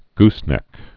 (gsnĕk)